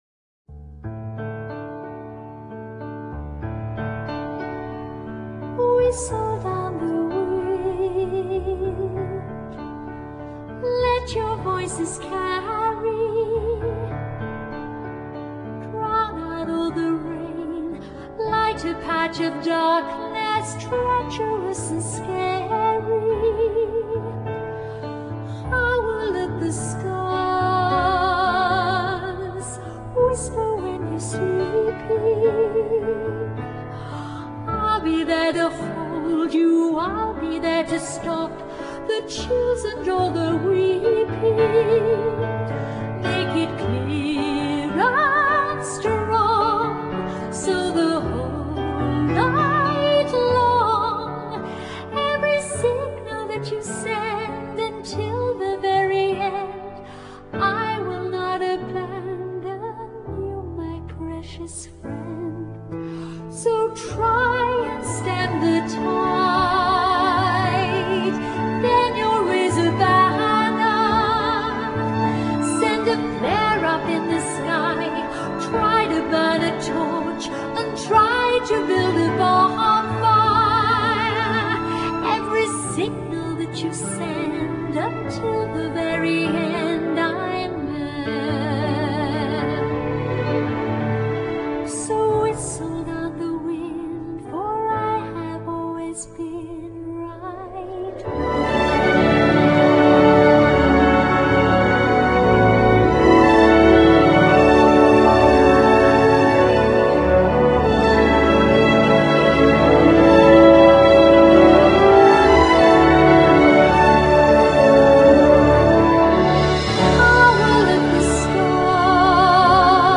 音乐类型：古典音乐